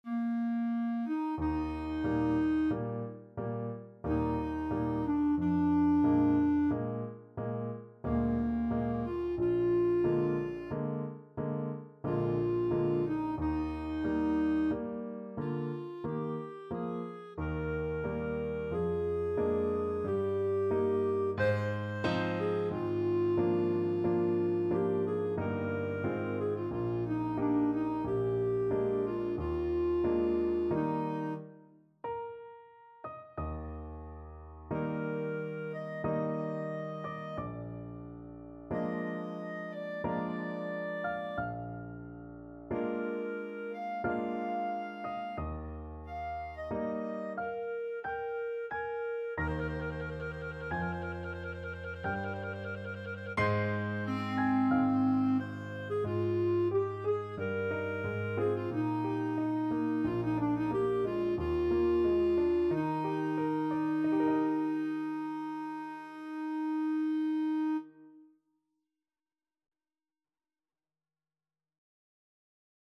3/4 (View more 3/4 Music)
Adagio =45
Classical (View more Classical Clarinet Music)